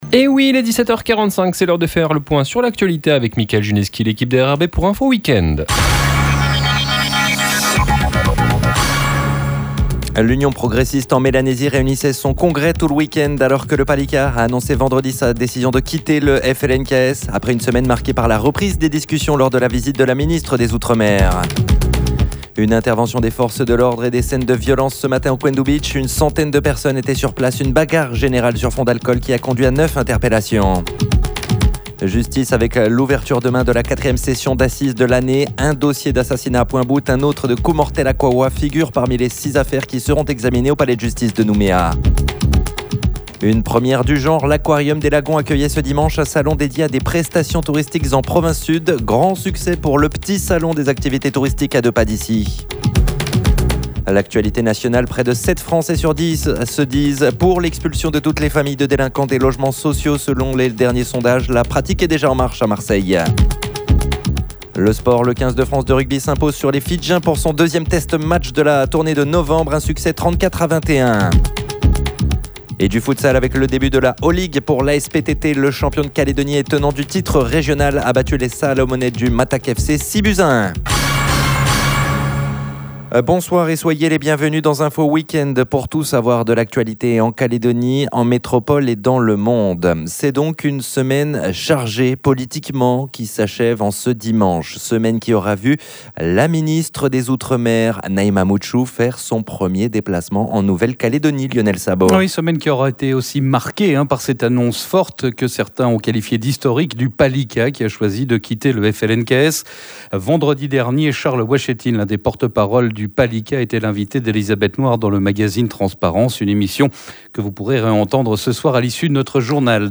Info-weekend, votre grand journal du weekend, pour tout savoir de l'actualité en Calédonie, en métropole et dans le Monde.